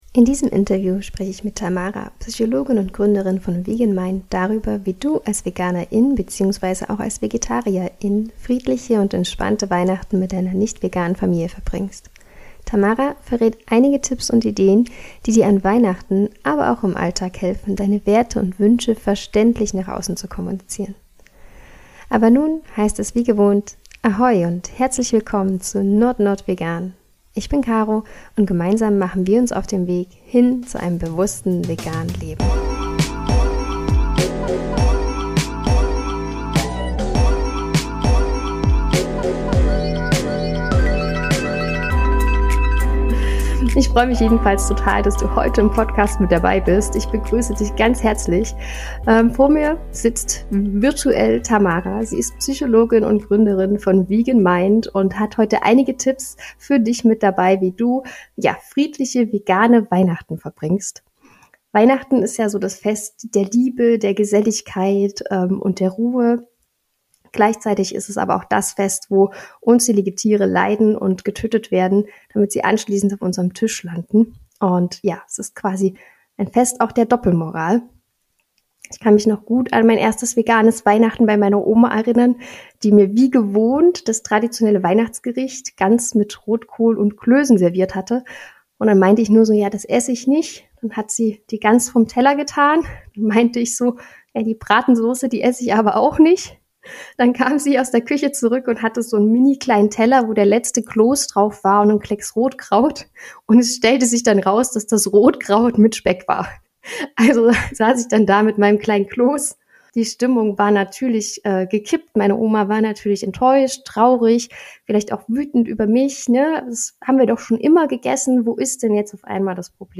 Du möchtest als Veganer:in ein friedliches Weihnachten im Kreise deiner Lieben feiern? In diesem Interview erfährst du wie dir das gelingt!